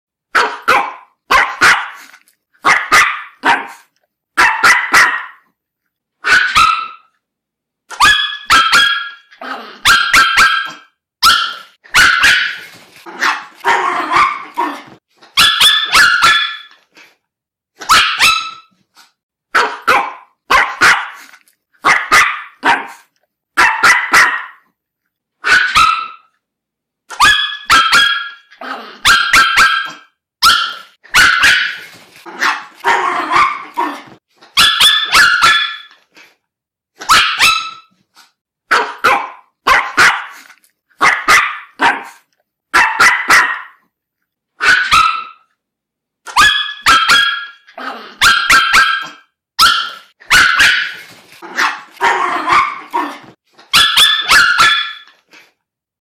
Dog Barking